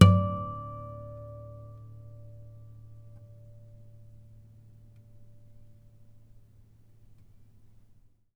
strings_harmonics
harmonic-01.wav